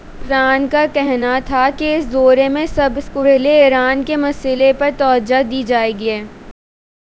Spoofed_TTS/Speaker_10/266.wav · CSALT/deepfake_detection_dataset_urdu at main